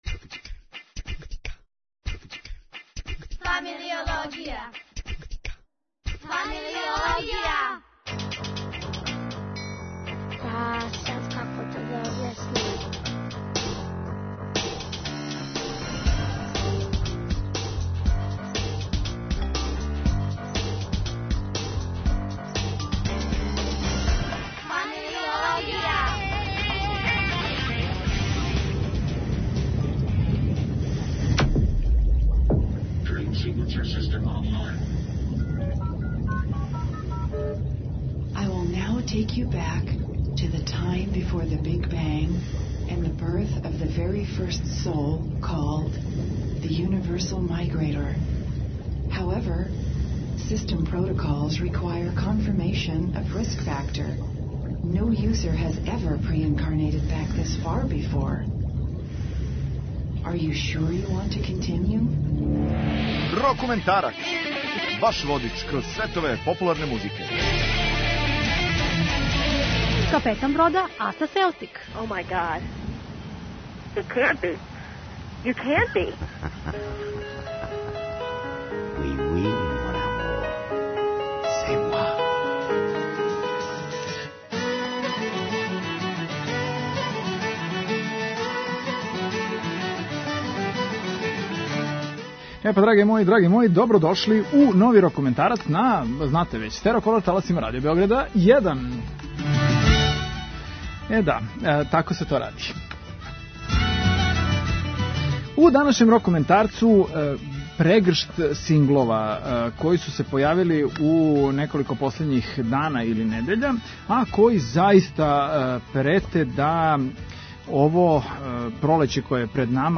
Зато што преслушавамо први део новог албума вечних младића авангардне и прогресивне рок сцене.